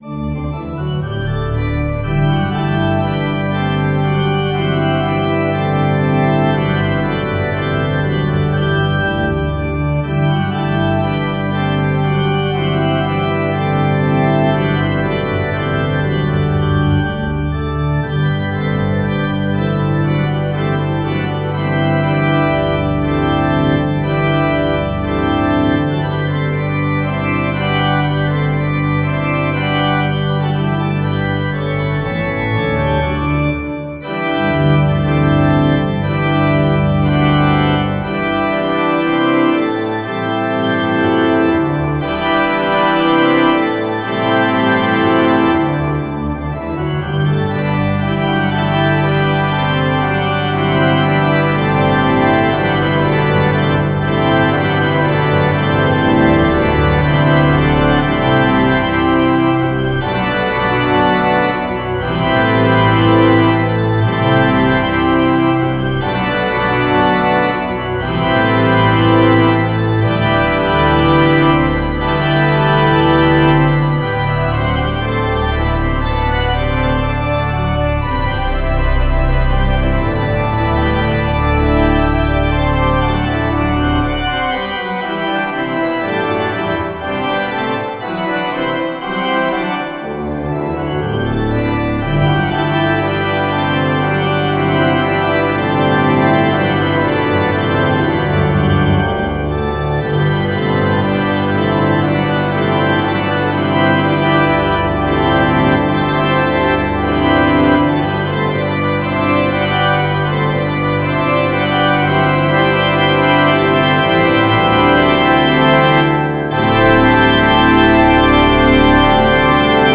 for organ A little reflection on repeated notes and simple scales in some composers' works in the Baroque era spawned the idea for this little allegro .